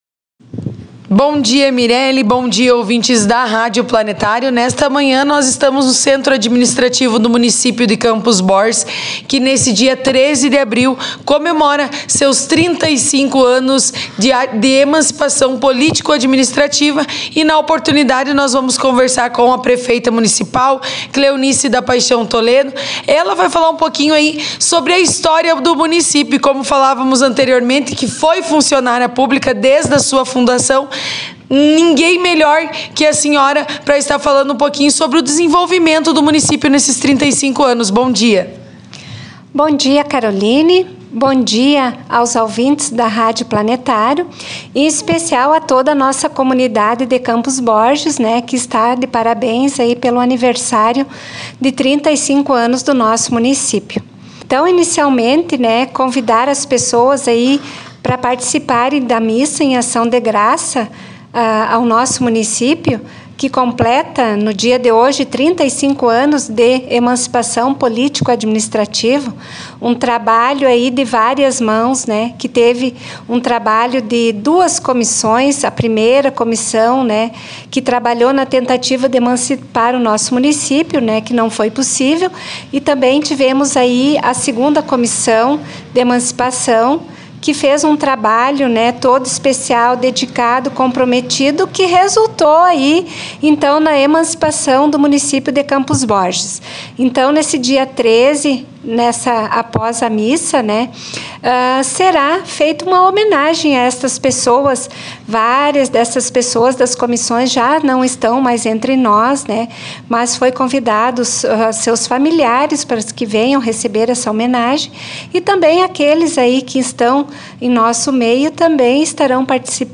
Nossa reportagem conversou com neste dia de comemoração com prefeita municipal, Cleonice da Paixão Toledo, funcionária pública de carreira no município desde sua emancipação, que destacou que o trabalho de cada administração e da comunidade nesses trinta e cinco anos de história foi o responsável pelo desenvolvimento do município.